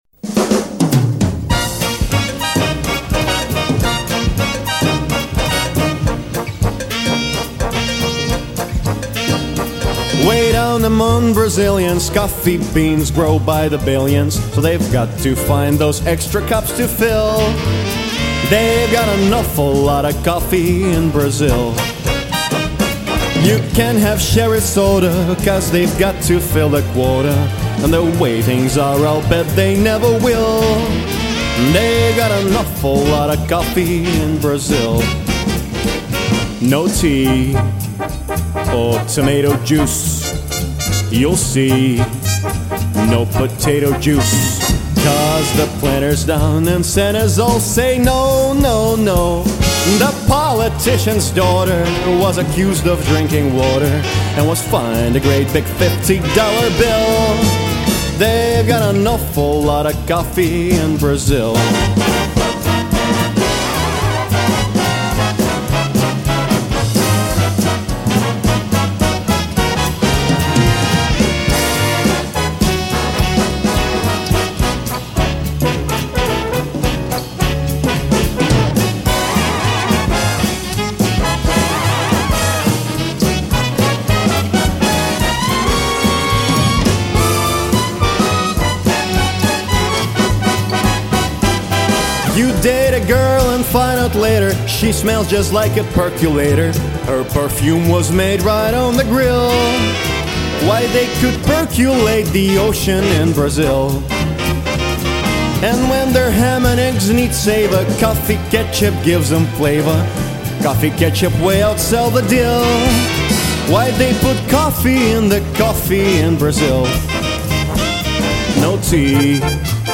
Певческий голос
Баритон
джазовый вокалист, англ-рус переводчик